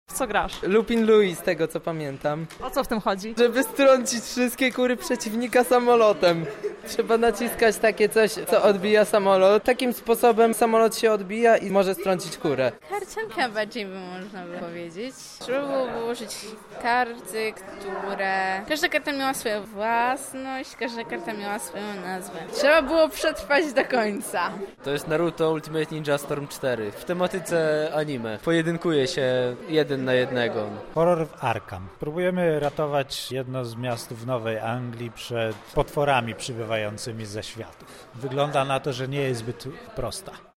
Nasza reporterka zapytała uczestników w jakie tytuły już zagrali.